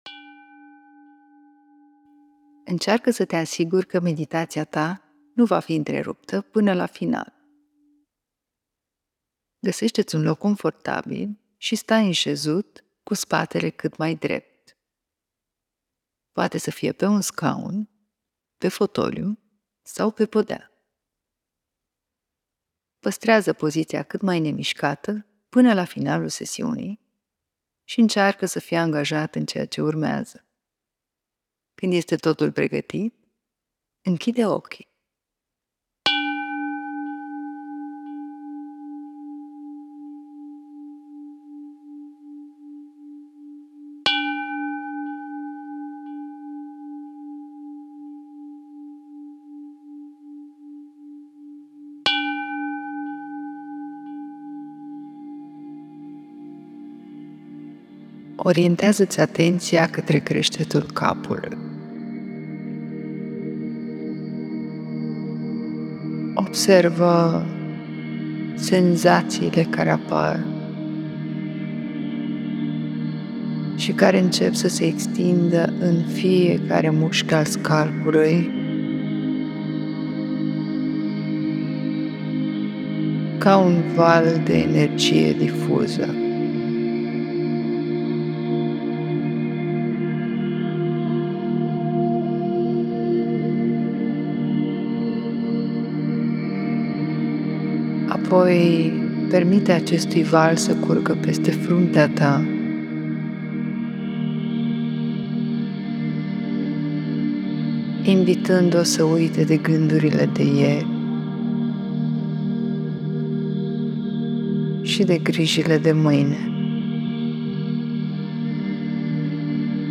Meditatii ghidate